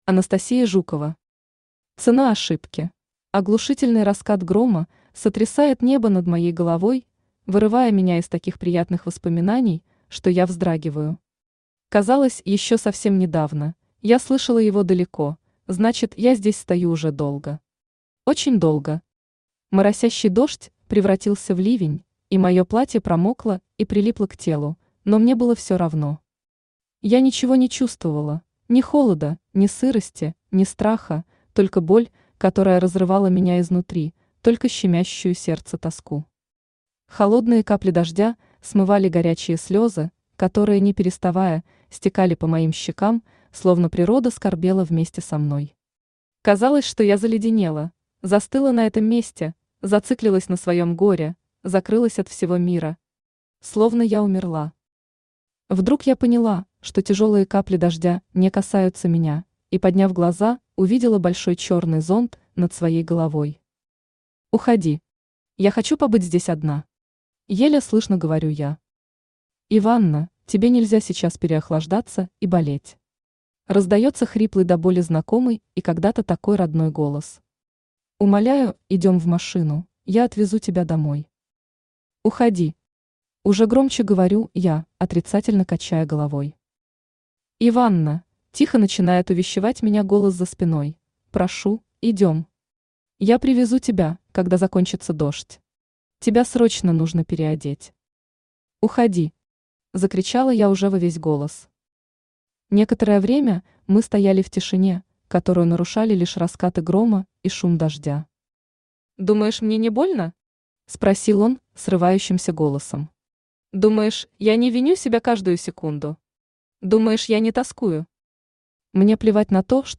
Аудиокнига Цена ошибки | Библиотека аудиокниг
Aудиокнига Цена ошибки Автор Анастасия Жукова Читает аудиокнигу Авточтец ЛитРес.